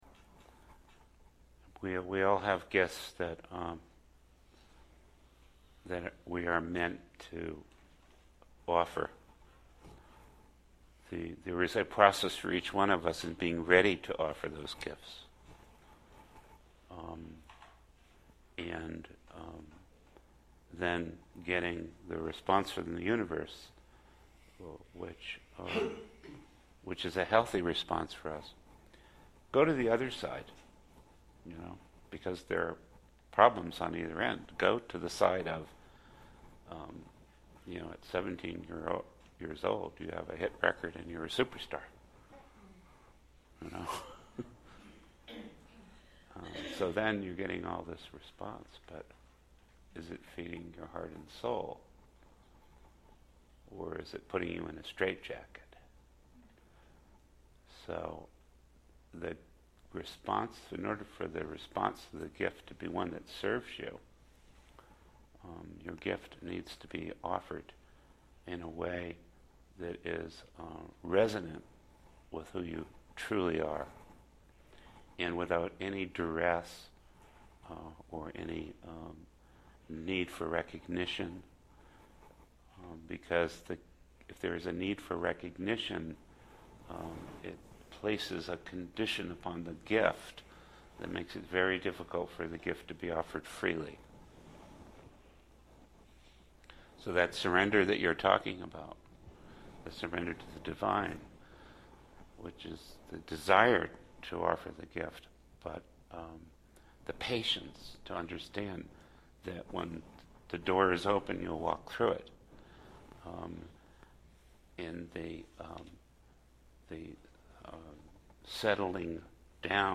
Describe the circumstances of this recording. Recordings from the Santa Fe 2009 retreat